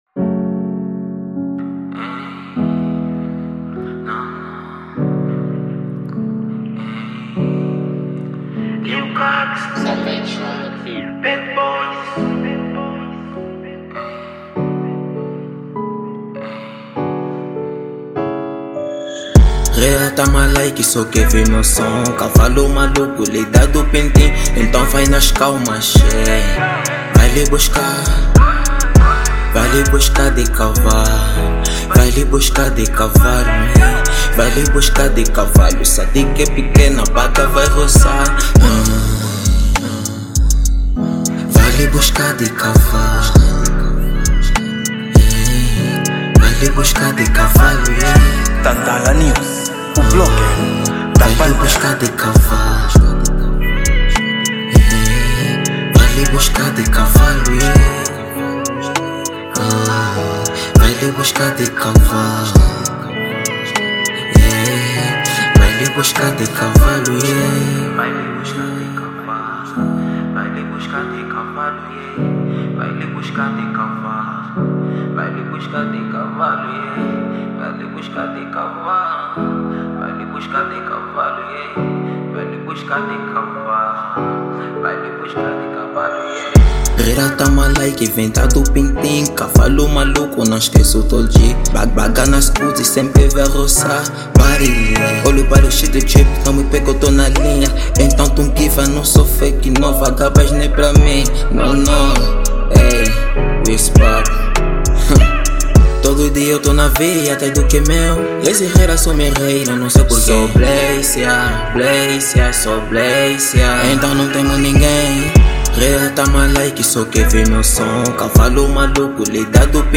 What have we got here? Gênero : TrapRap